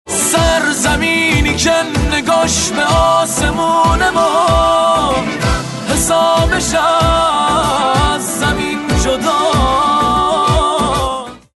زنگ موبایل
رینگتون پرانرژی و باکلام